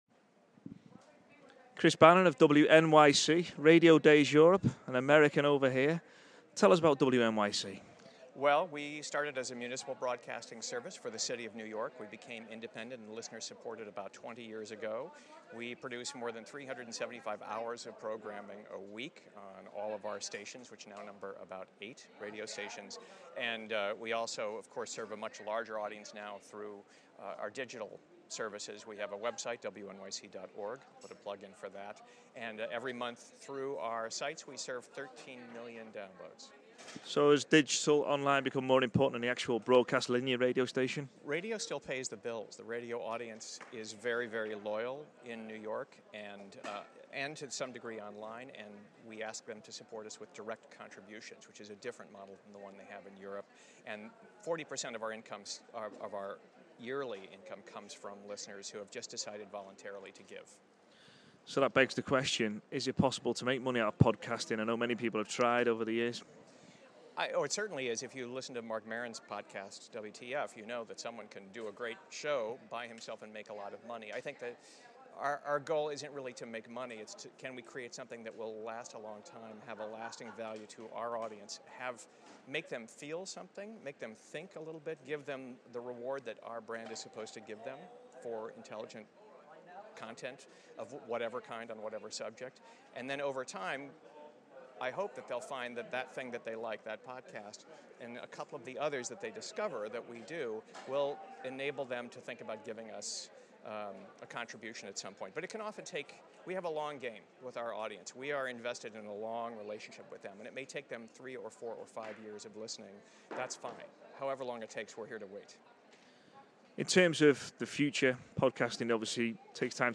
RadioToday Live Interviews